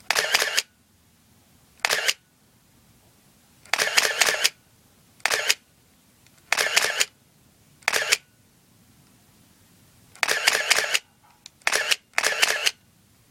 相机 " EOS3 Shutter05 x2
描述：来自旧佳能EOS 3相机的快门声。相机中没有胶片，但是我放了一些电池，它发出一些甜蜜的声音。第四代iPod touch，使用media.io转换并使用Audacity编辑。
标签： 相机咔嚓咔嚓扣 摄影 摄像 单镜头反光 EOS 单反 佳能EOS-3 照片 快门
声道立体声